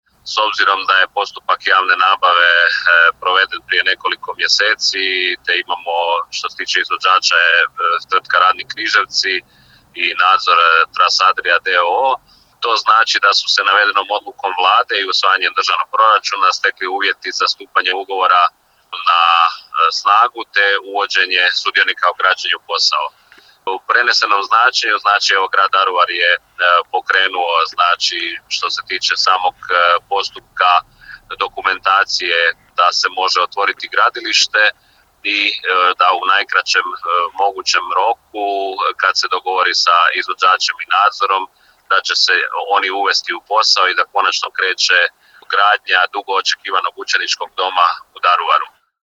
Što ova Odluka znači za Daruvar u postupku realizacije velikog i značajnog projekta, programske sastavnice Operativnog programa za češku i slovačku nacionalnu manjinu i koje aktivnosti nakon donošenja ove Odluke slijede, pojasnit će zastupnik za češku i slovačku nacionalnu manjinu u Hrvatskom saboru Vladimir Bilek: